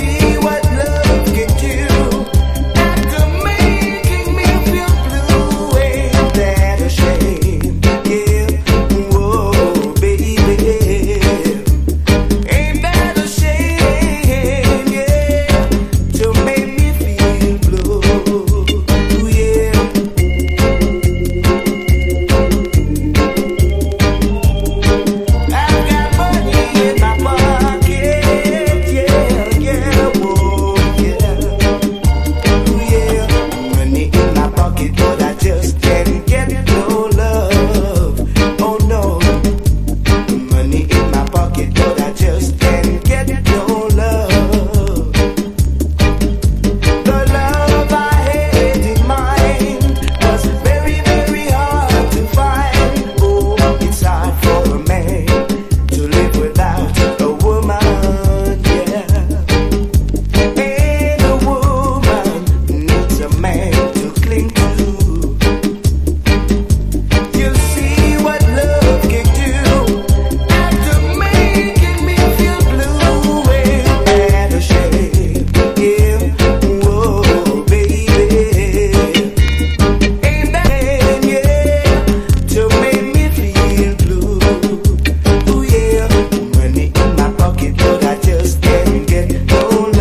1. REGGAE >
(全編チリノイズ入ります)